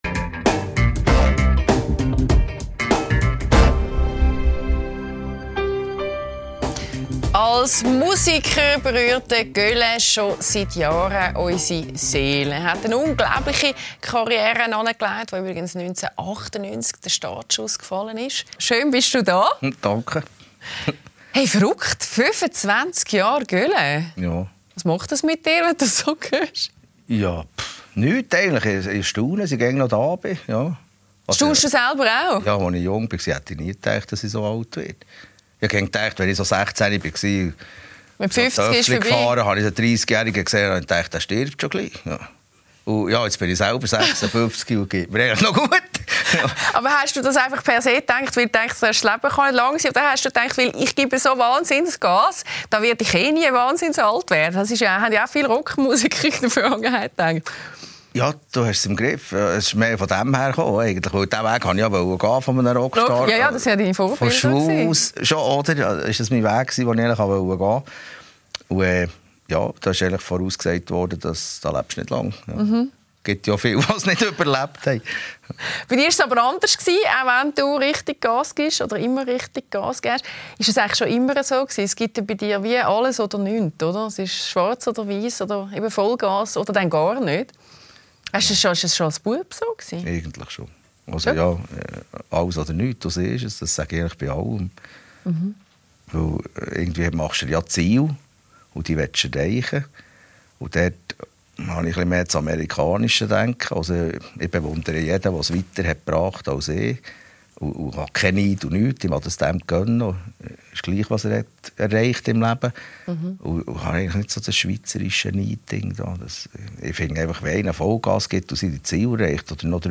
Seit 25 Jahren rockt Gölä die Bühnen. In der Talkshow «Lässer» verrät der erfolgreiche Mundart-Rocker, dass er eigentlich nicht gerne im Mittelpunkt steht, gibt Einblicke in seine Kindheit und teilt die prägendsten Momente seines Lebens.